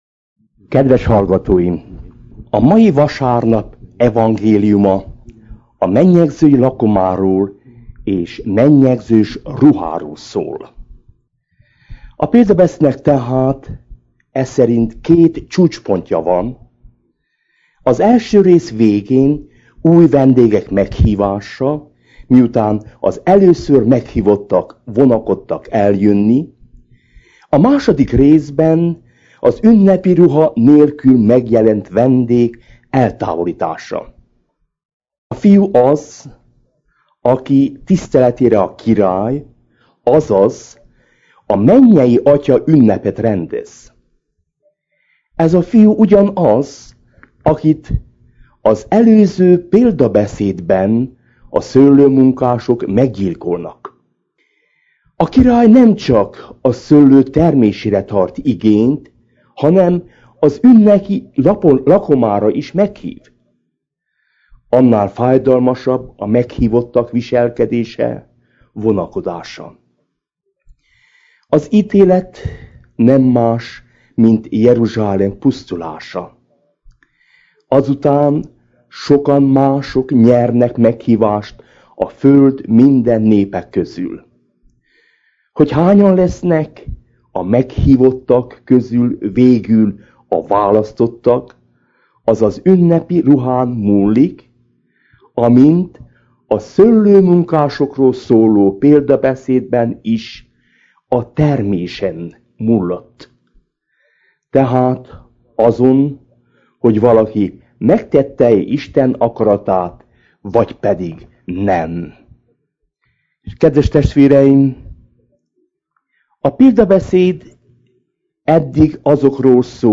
a Clevelandi Kelet-oldali Szent Erzsébet Katolikus Templomból. https